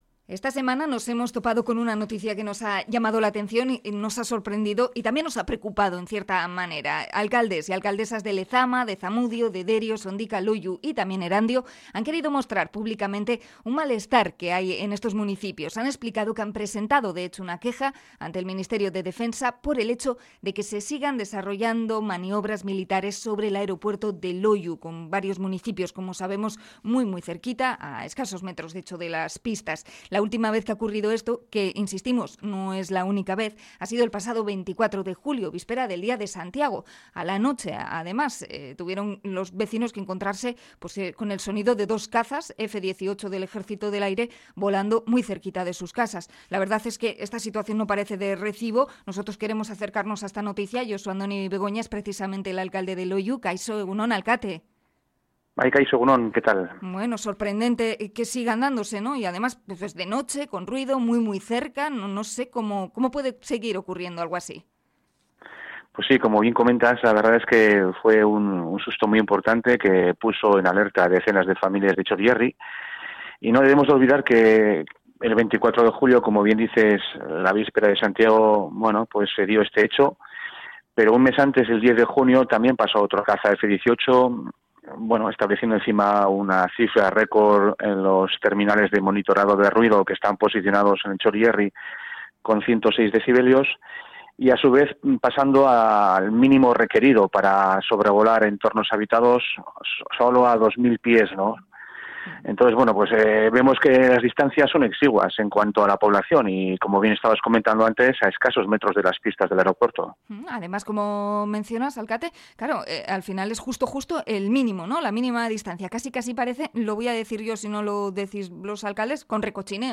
Entrevista al alcalde de Loiu por los vuelos militares en la zona
ESta mañana hemos contactado con Josu Andoni Begoña, alcalde de Loiu, a raíz de que esta semana alcaldes del Txorierri hayan denunciado ante el Ministerio de Defensa las maniobras de dos cazas F-18 en las inmediaciones del Aeropuerto de Loiu la noche del 24 de julio, por las que se registraron 102 decibelios en los municipios colindantes.